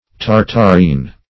Search Result for " tartarine" : The Collaborative International Dictionary of English v.0.48: Tartarine \Tar"tar*ine\, n. (Old Chem.) Potassium carbonate, obtained by the incineration of tartar.